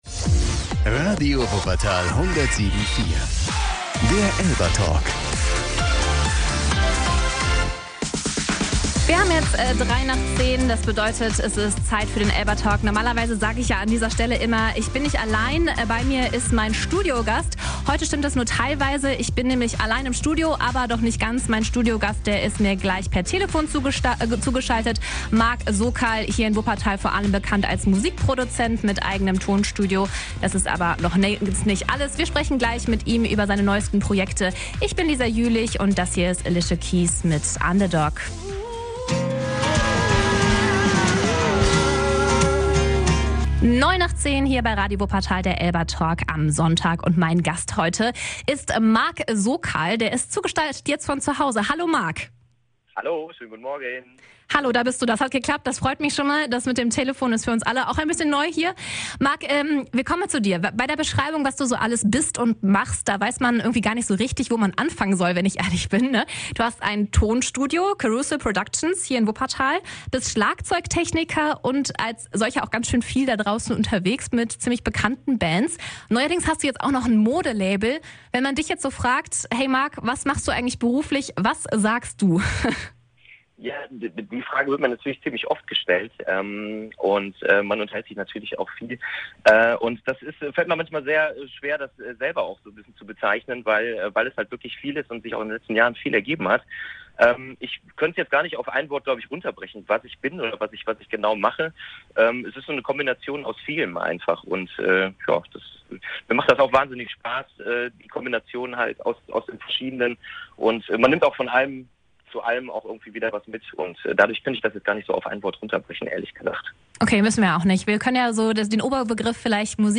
elba_talk_mitschnitt.mp3